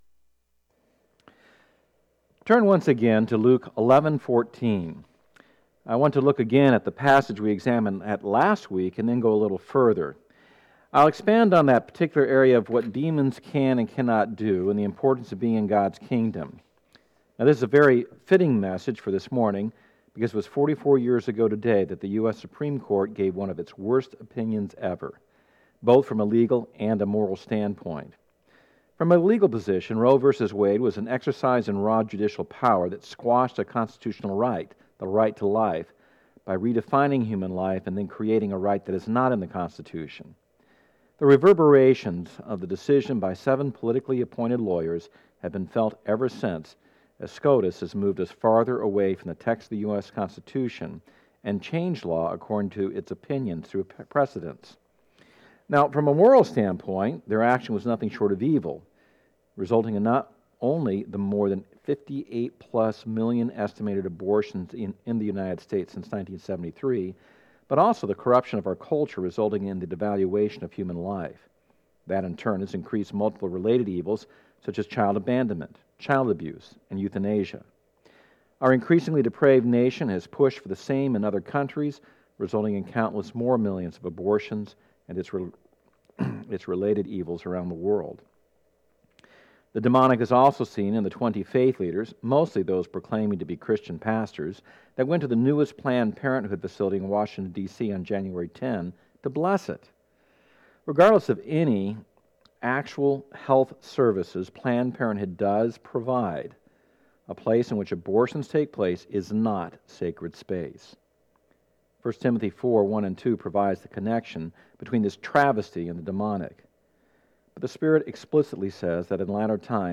Sermons 2017